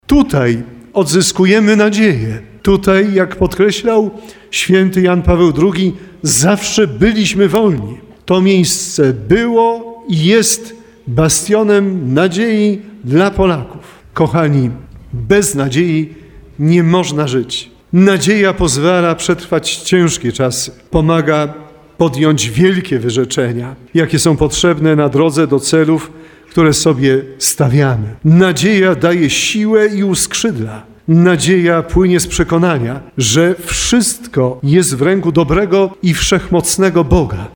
Pątnicy po 9- dniowej wędrówce uczestniczyli we Mszy św., której przewodniczył biskup tarnowski Andrzej Jeż.